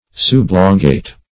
Subelongate \Sub`e*lon"gate\, a. Not fully elongated; somewhat elongated.